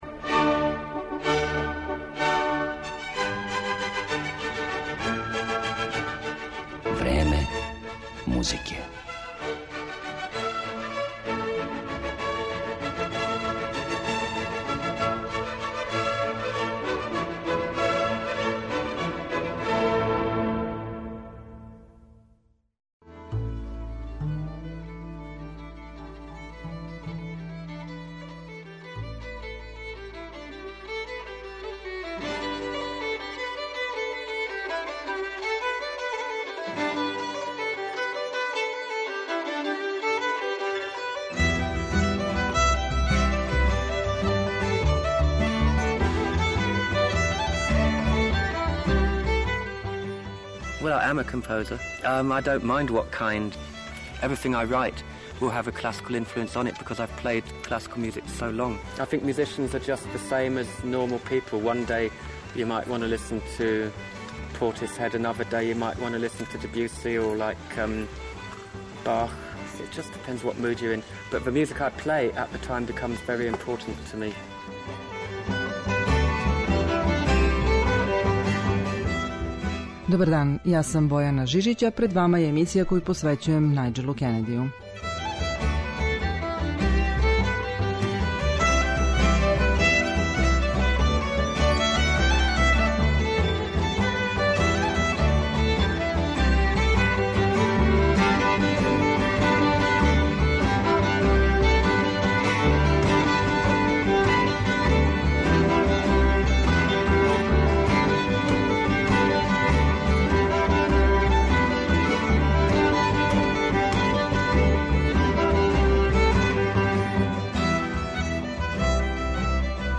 Поред врхунских интерпретација музике разноврсних стилова и жанрова, увек обележених изразитим личним печатом, моћи ћете да чујете и интервју снимљен са овим славним музичарем приликом његовог првог гостовања у Београду.